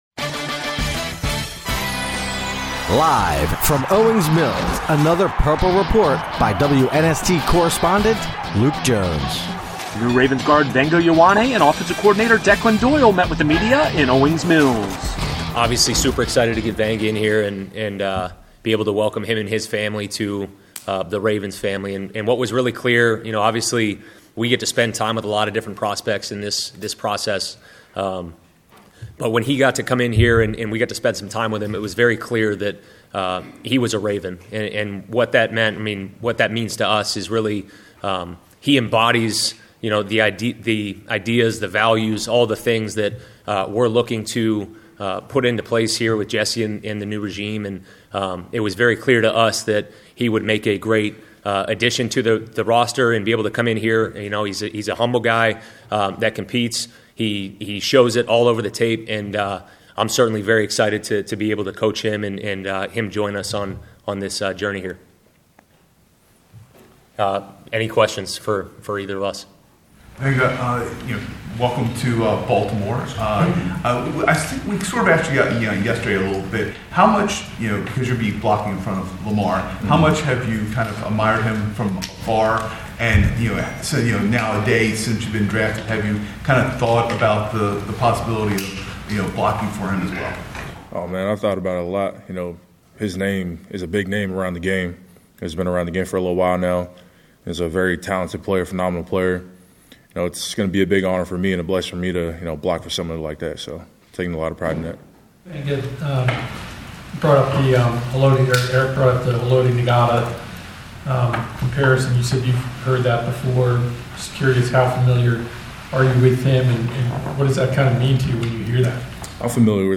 Vega Ioane arrives in Owings Mills for introductory press conference